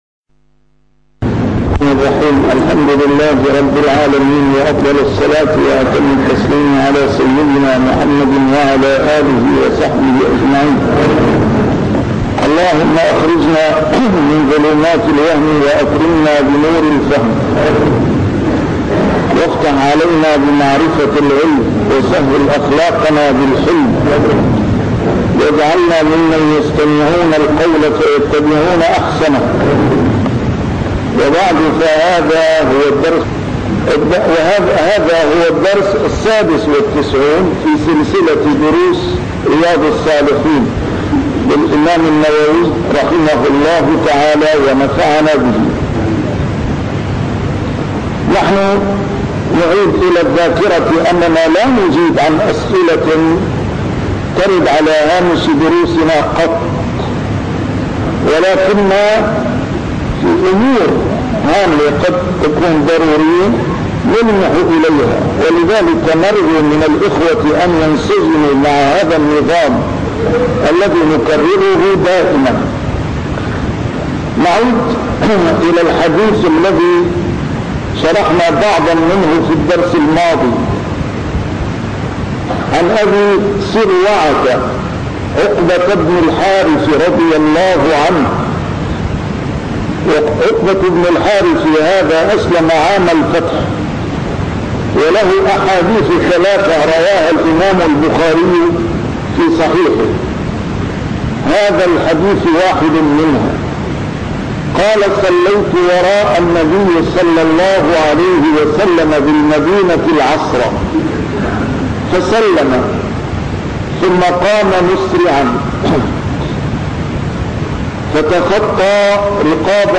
A MARTYR SCHOLAR: IMAM MUHAMMAD SAEED RAMADAN AL-BOUTI - الدروس العلمية - شرح كتاب رياض الصالحين - 96- شرح رياض الصالحين: المبادرة إلى الخيرات